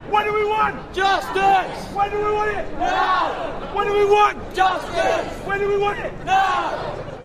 Riot protest demonstration angry mob chant kit UK